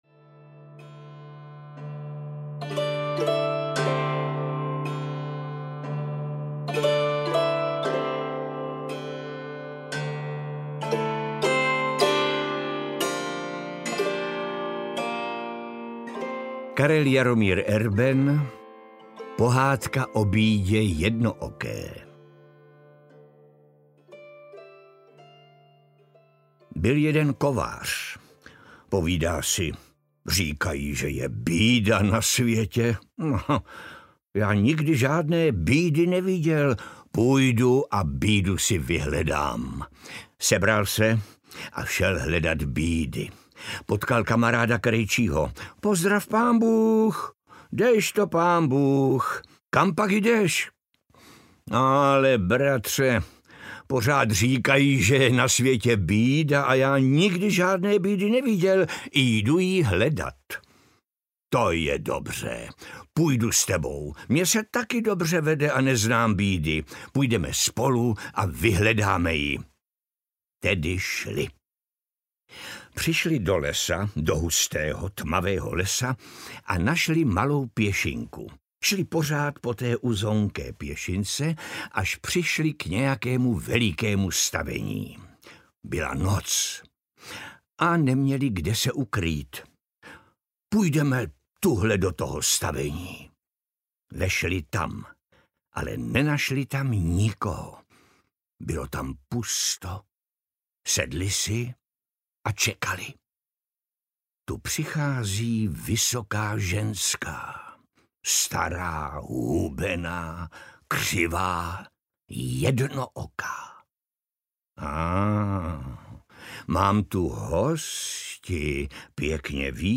Pohádkové poslouchání audiokniha
Ukázka z knihy
• InterpretPetr Kostka